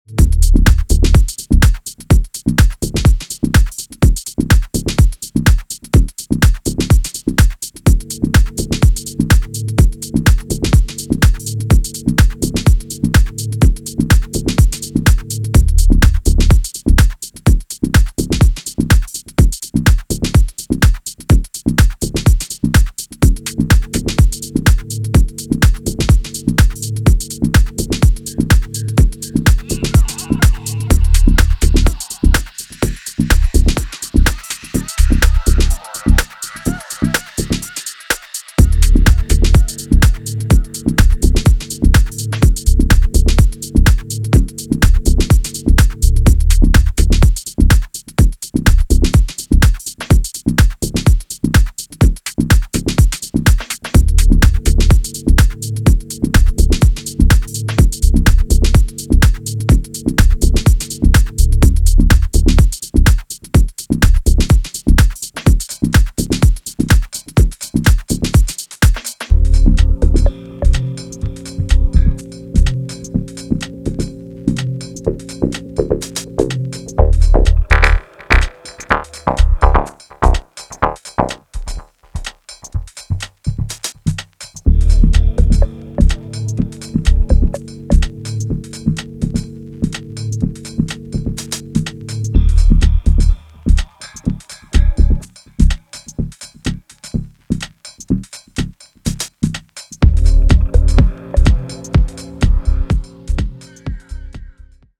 深夜系オルガンパッドと狂おしくヴォリュームを上げるアシッドが妖しくゆらめく